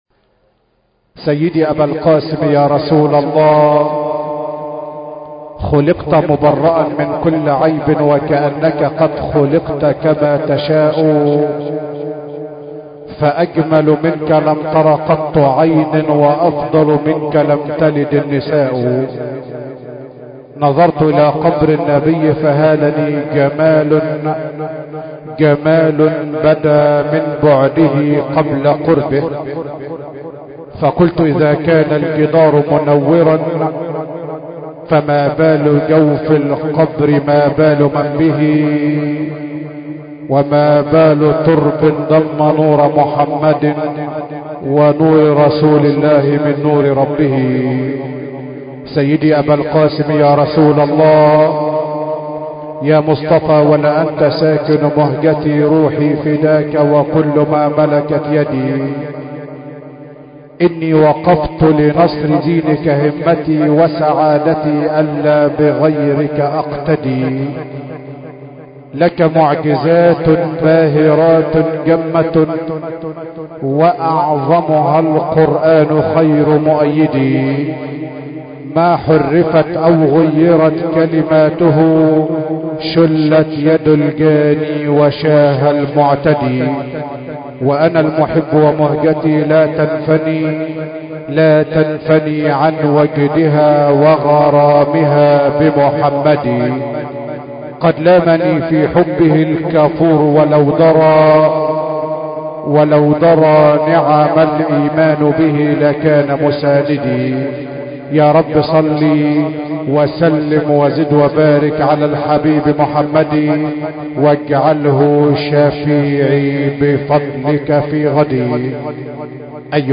به گزارش خبرگزاری بین‌المللی قرآن(ایکنا) ، محمود شحات‌انور، قاری برجسته مصری در نخستین روزهای ماه مبارک رمضان به تلاوت آیاتی از سوره مبارکه «بقره» پرداخت. شحات‌انور در محفل قرآنی که با حضور قرآن‌دویستان و جمع کثیری از مردم برگزار شد به مدت 40 دقیقه به تلاوت قرآن پرداخت که با استقبال مردم روبرو شد و مورد تشویق آنان قرار گرفت. استفاده از الحان زیبا و همچنین انتخاب آیاتی متناسب با ماه مبارک رمضان و تکرارهای تأثیرگذار، یکی از ویژگی‌های تلاوت این قاری برجسته مصری بود.